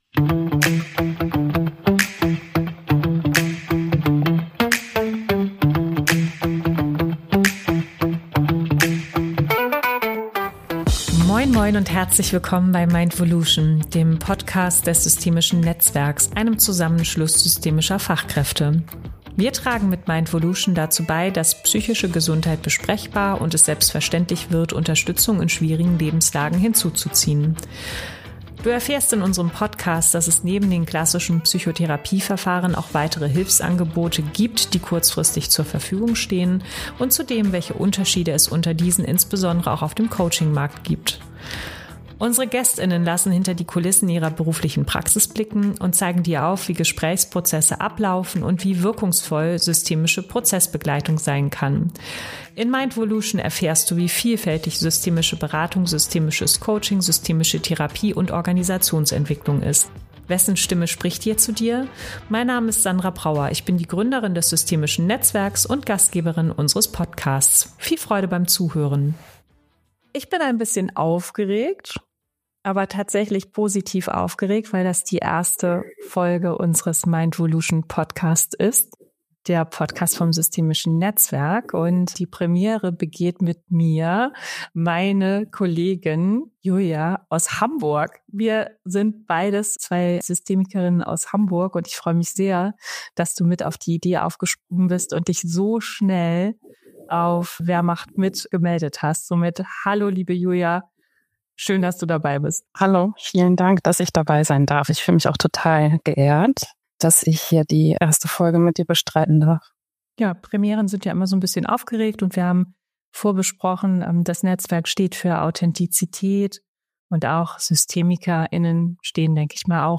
Beschreibung vor 1 Jahr Mindvolution ist der Podcast des systemischen Netzwerks – einem Zusammenschluss systemischer Berater:innen, Coaches und Therapeut:innen. Wir sprechen offen und ehrlich über psychische Gesundheit, systemische Prozessbegleitung und darüber, wie Beratung und Coaching im Alltag wirklich funktionieren können.